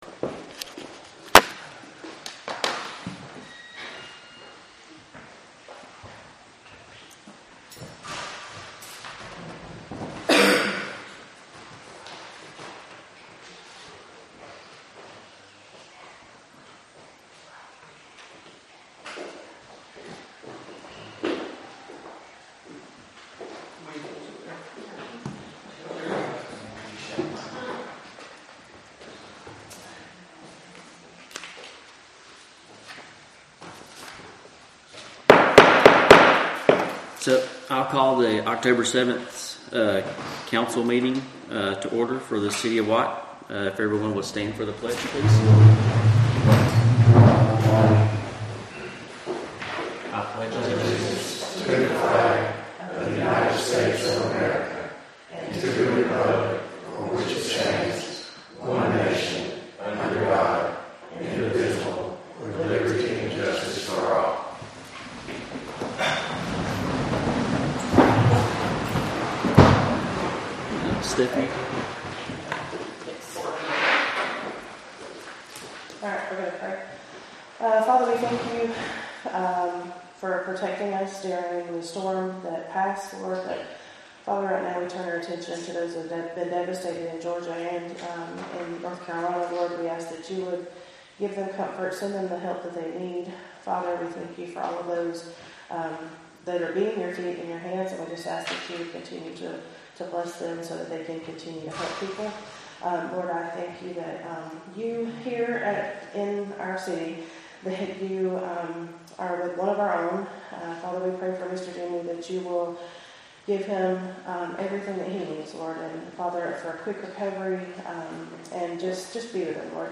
Oct-7th-2024-council-meeting.mp3